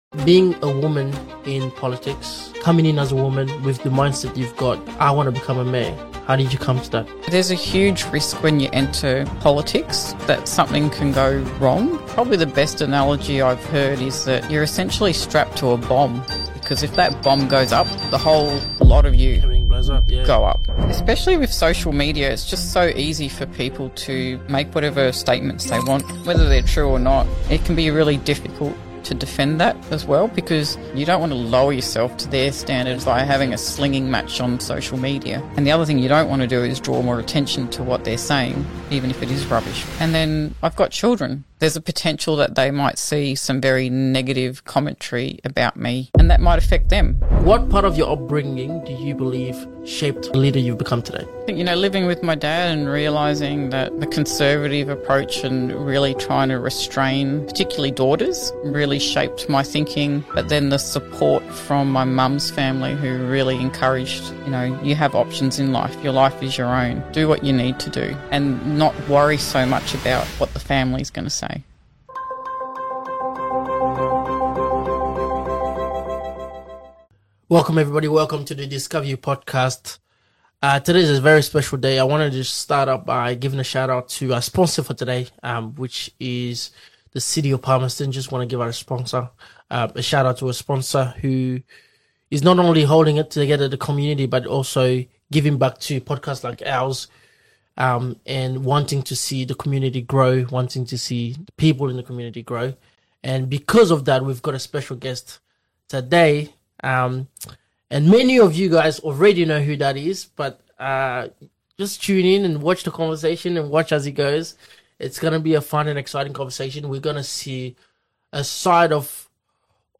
The Truth Behind Palmerston Council’s Shock Dismissal 😮 | Mayor Athina Speaks Out